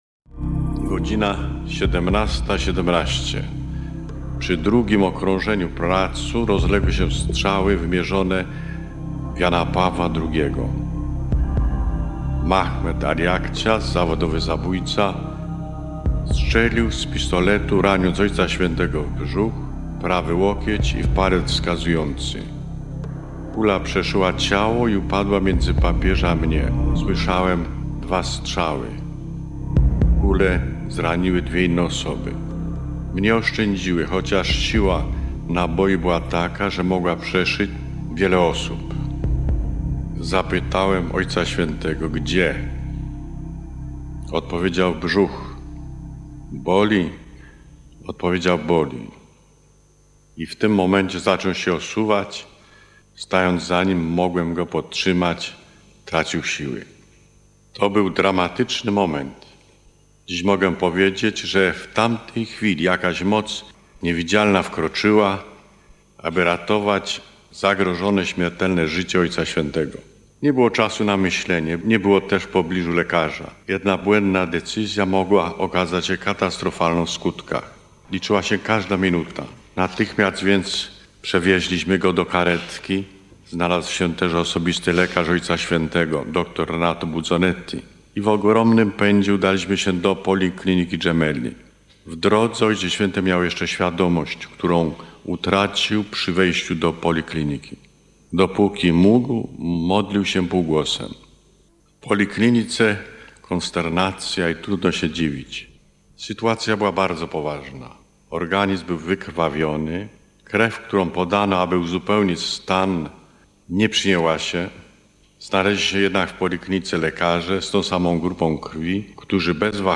Relacja ks. Stanisława Dziwisza - światka zamachu